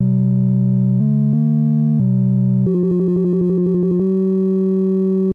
Arcade[edit]